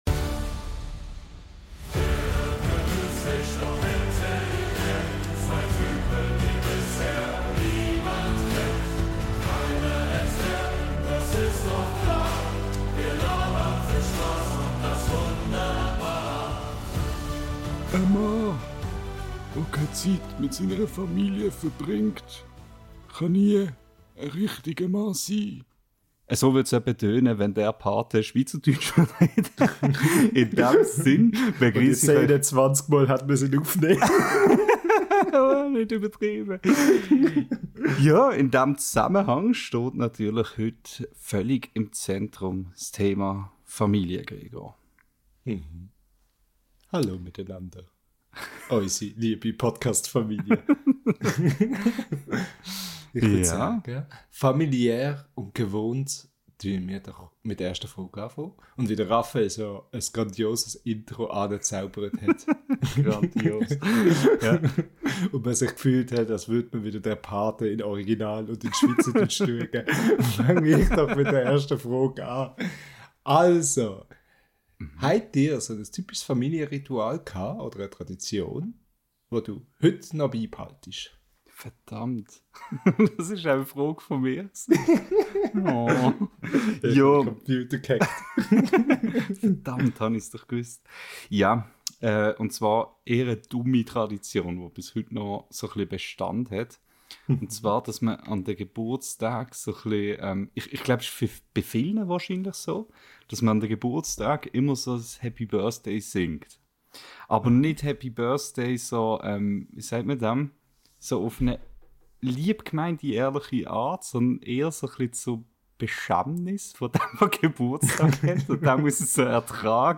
Also haben wir uns gedacht – passt doch perfekt auch als Thema für eine neue Folge unseres schweizerdeutschen Podcastes! Wir sprechen über seltsame Familientraditionen, peinliche Anekdoten und fragen uns: Wie weit würden wir wirklich für unsere Familie gehen? Zwischen viel Gelächter, ein bisschen Ernst und – versprochen – keinerlei Tipps für illegale Aktivitäten.